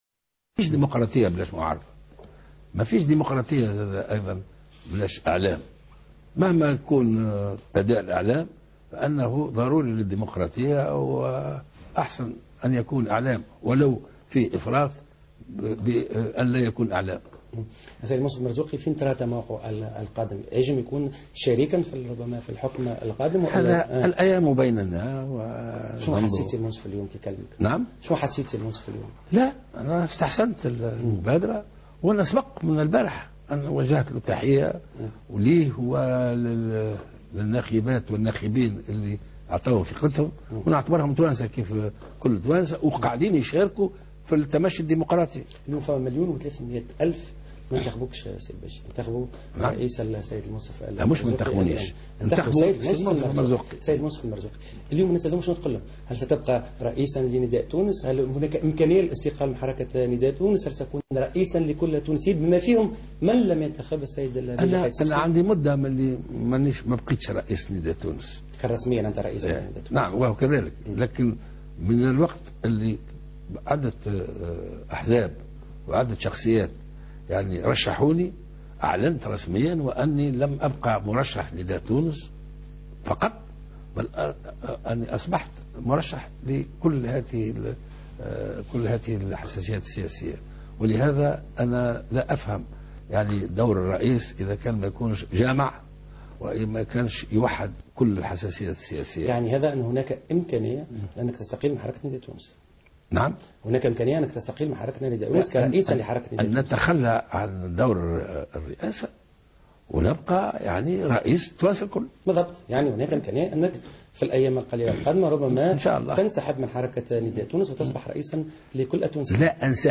Le futur président de la République, Béji Caïd Essebsi, a accordé ce lundi soir une interview à Al Wataniya quelques heures après la proclamation des résultats de l'ISIE.